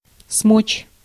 Ääntäminen
France (Paris): IPA: [pu.vwaʁ]